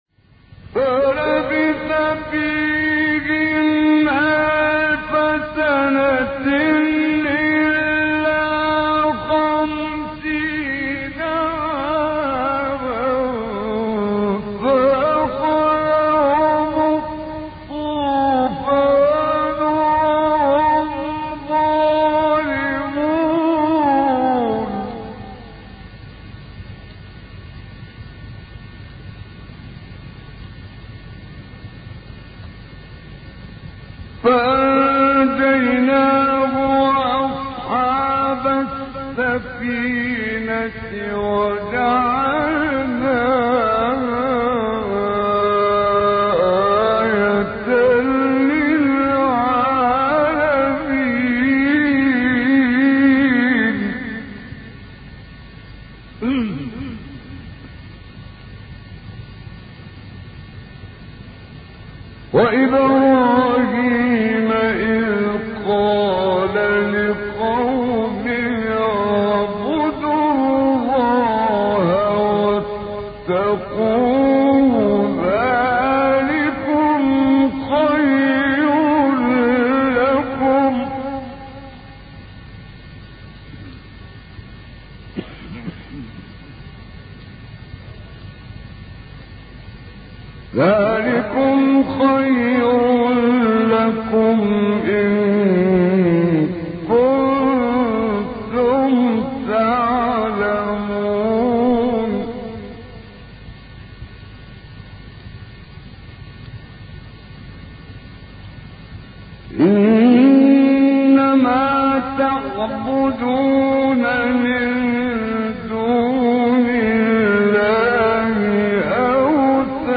سوره : عنکبوت آیه: 14-17 استاد : محمد عمران مقام : مرکب خونی(حجاز * بیات) قبلی بعدی